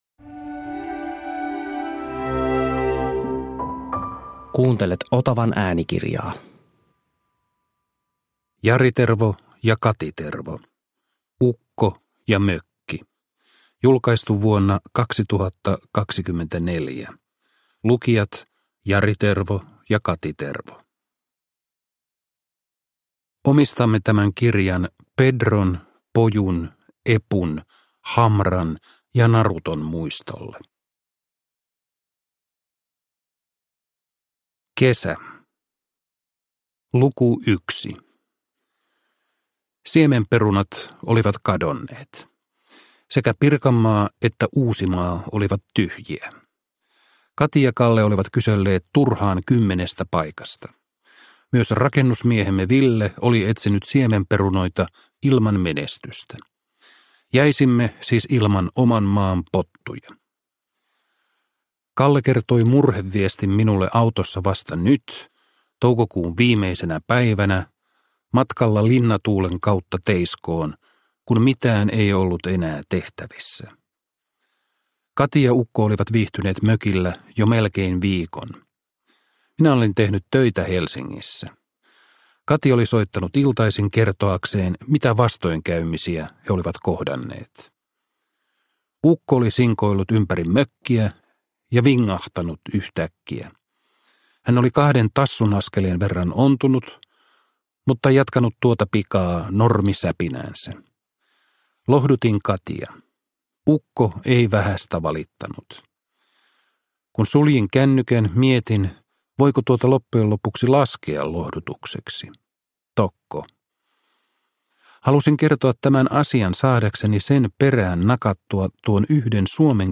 Ukko ja mökki – Ljudbok